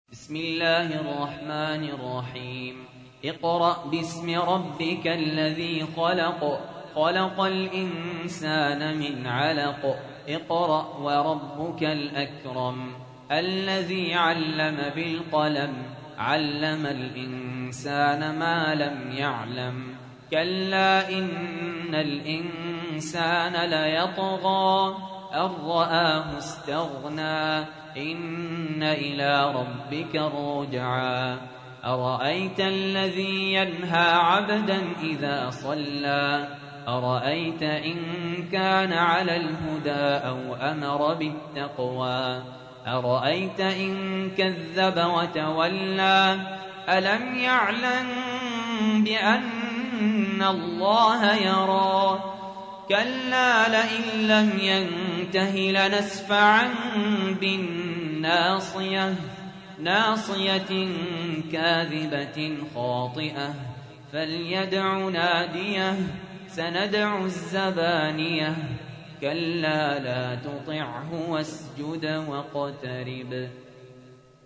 تحميل : 96. سورة العلق / القارئ سهل ياسين / القرآن الكريم / موقع يا حسين